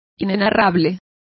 Complete with pronunciation of the translation of indescribable.